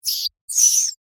ratDie2.mp3